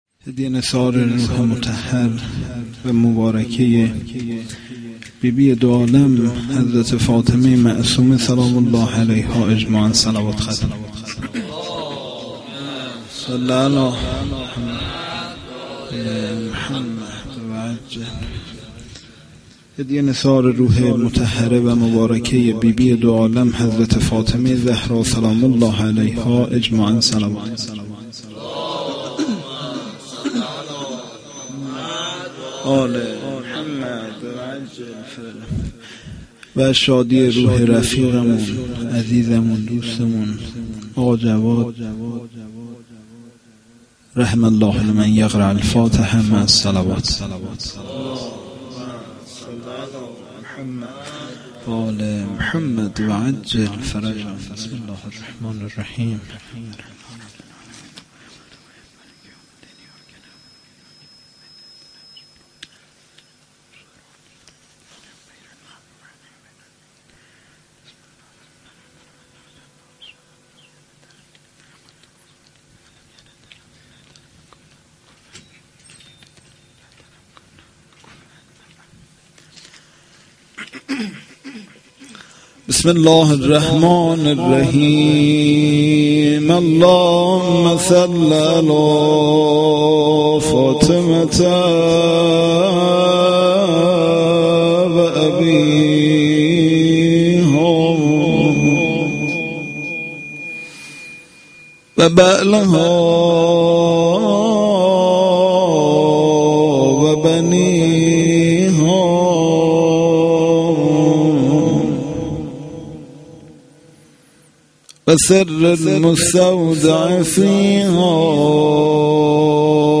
shahadate-h.-masoumeh-s-92-rozeh.mp3